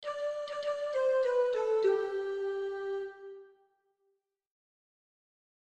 Interpreta estes pequenos motivos melódicos extraídos da melodía principal como quecemento previo.